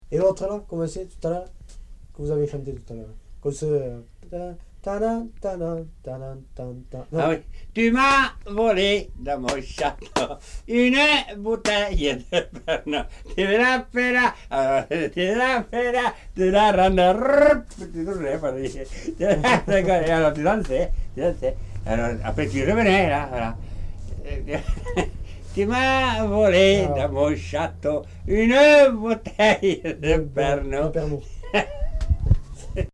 Aire culturelle : Lauragais
Lieu : Villaudric
Genre : chant
Effectif : 1
Type de voix : voix d'homme
Production du son : chanté
Danse : polka des bébés